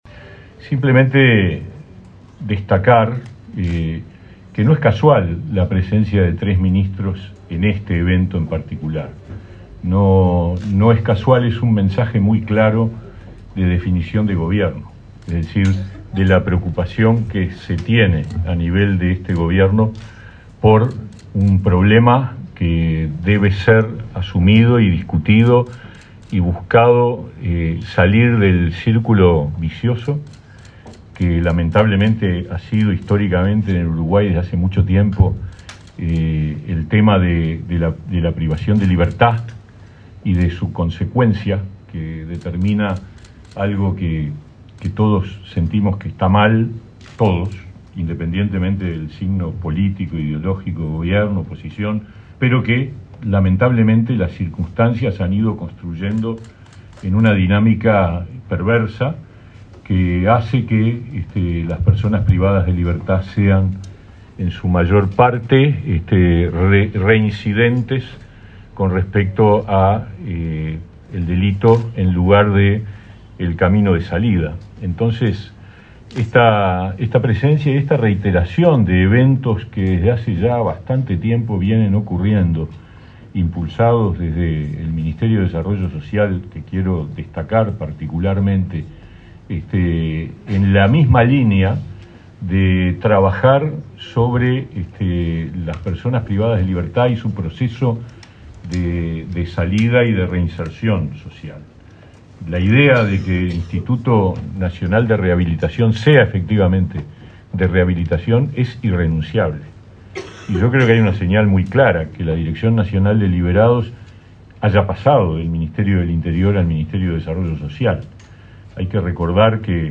Palabra de autoridades en acto de Inefop
Palabra de autoridades en acto de Inefop 06/12/2023 Compartir Facebook X Copiar enlace WhatsApp LinkedIn Este miércoles 6 en Montevideo, el ministro de Trabajo, Pablo Mieres; su par de Desarollo Social, Martín Lema, y el titular de Interior, Nicolás Martinelli, participaron del acto de firma de un convenio entre autoridades de Inefop y el Instituto Nacional de Rehabilitación (INR).